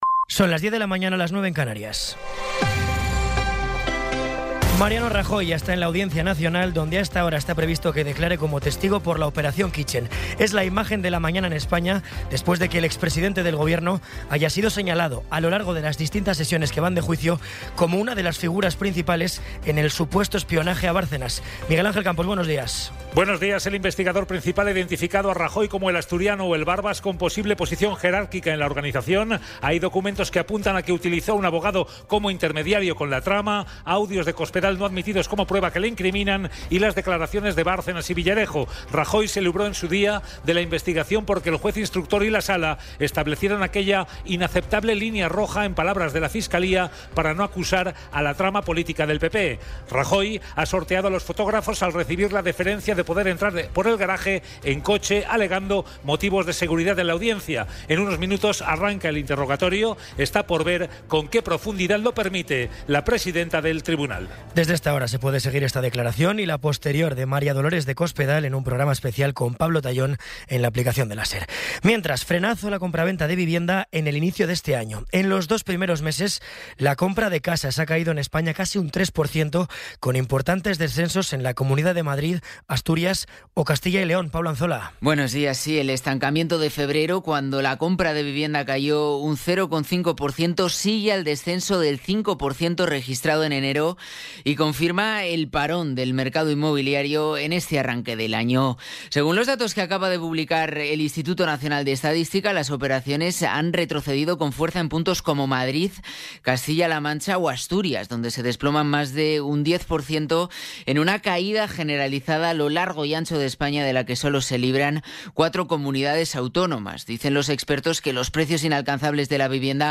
Resumen informativo con las noticias más destacadas del 23 de abril de 2026 a las diez de la mañana.